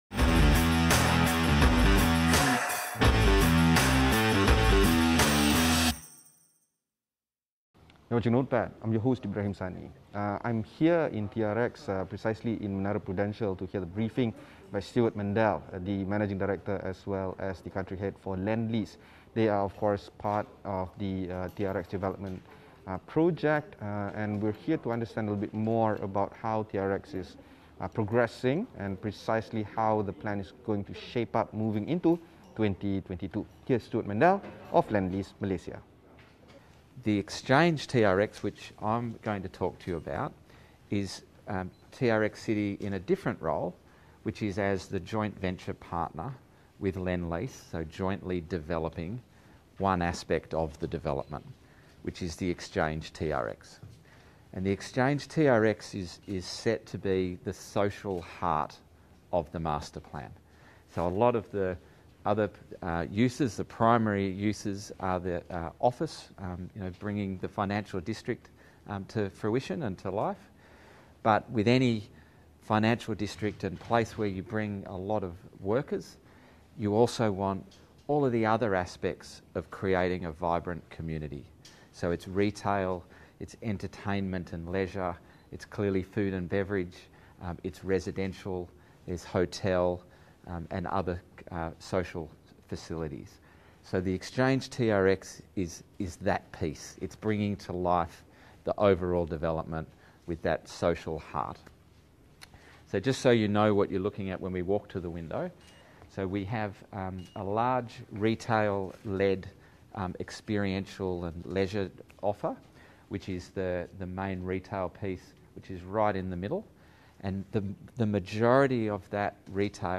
on-site at TRX